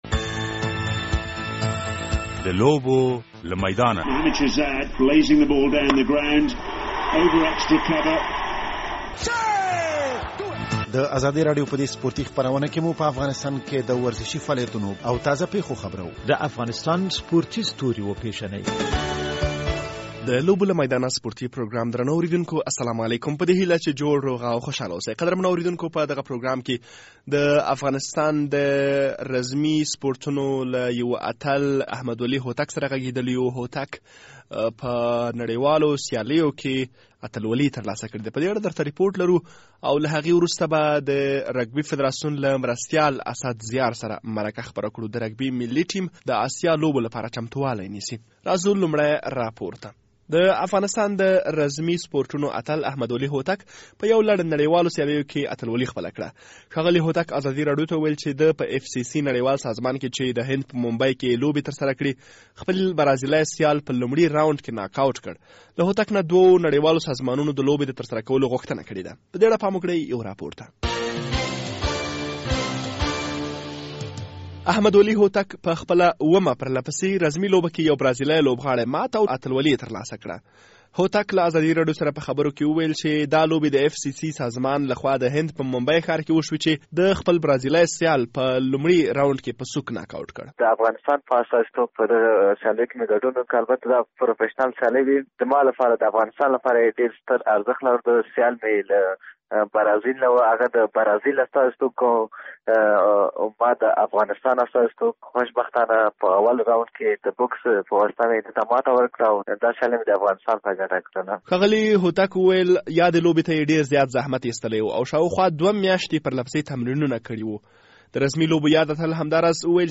د لوبو له میدانه سپورټي پروګرام خپرېدو ته چمتو دی.